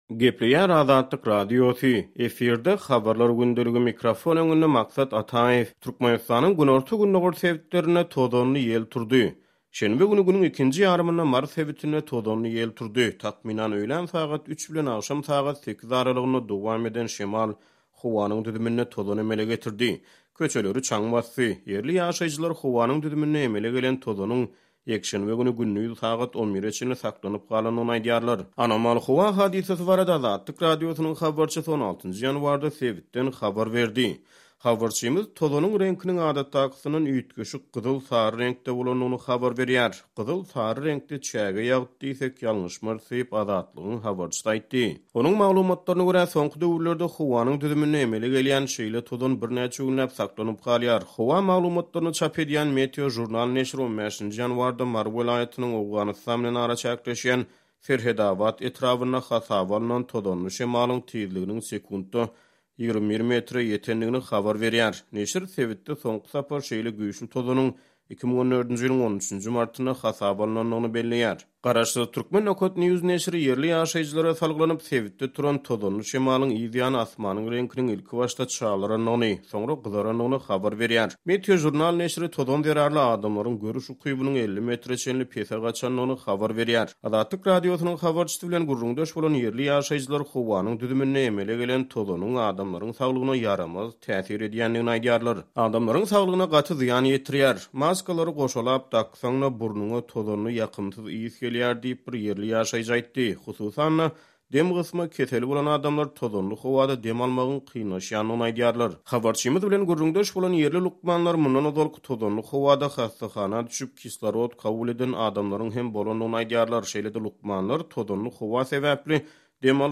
Anomal howa hadysasy barada Azatlyk Radiosynyň habarçysy 16-njy ýanwarda sebitden habar berdi.